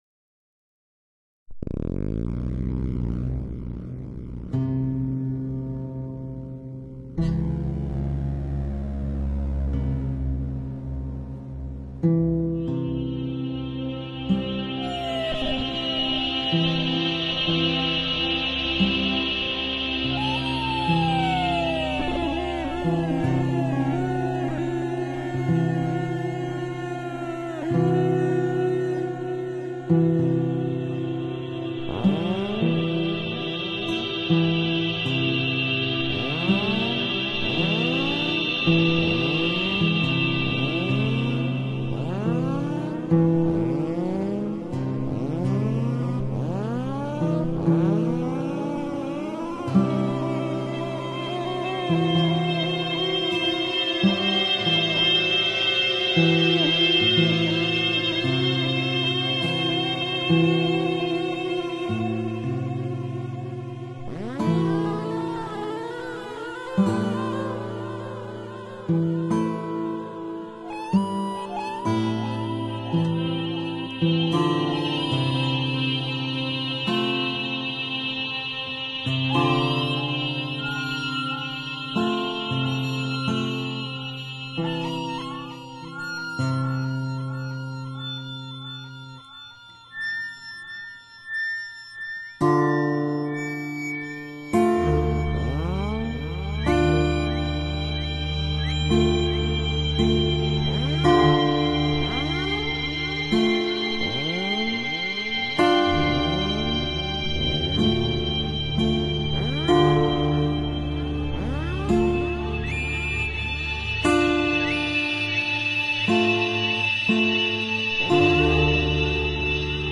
instrumental piee
Korg Microsyth and Guitar mainly